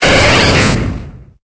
Cri de Pyroli dans Pokémon Épée et Bouclier.